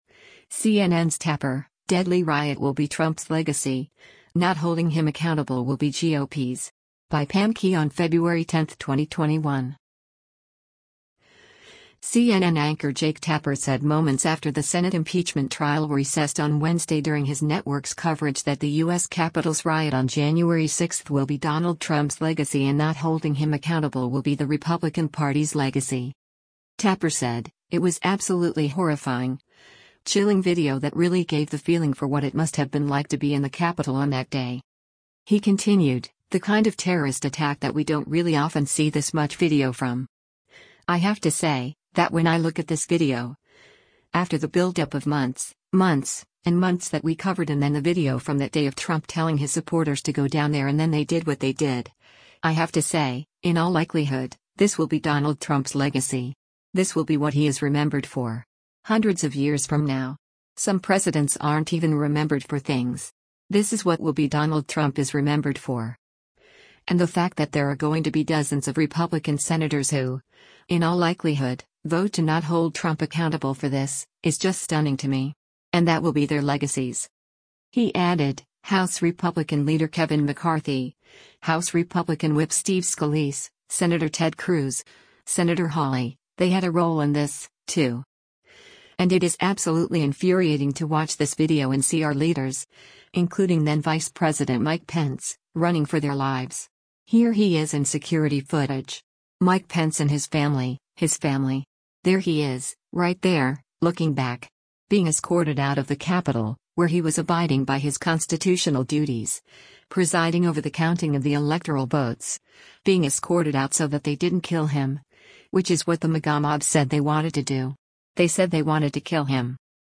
CNN anchor Jake Tapper said moments after the Senate impeachment trial recessed on Wednesday during his network’s coverage that the U.S. Capitol’s riot on January 6 will be “Donald Trump’s legacy” and not holding him accountable will be the Republican Party’s legacy.